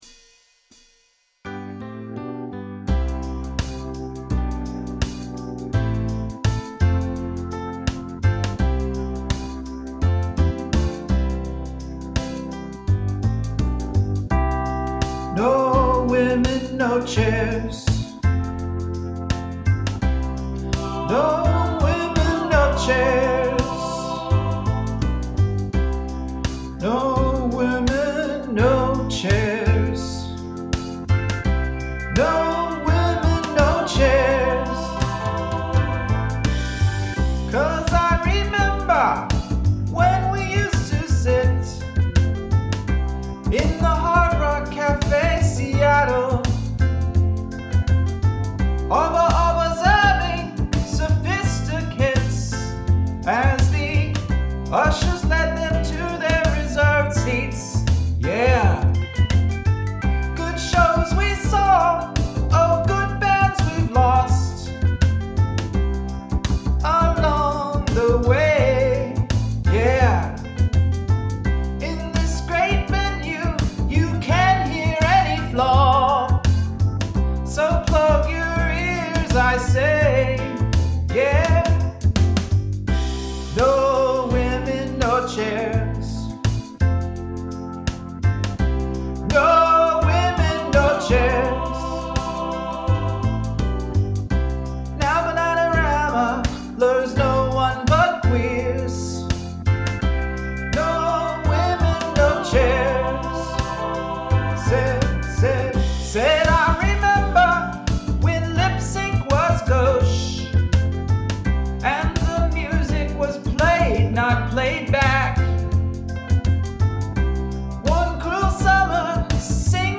musical humor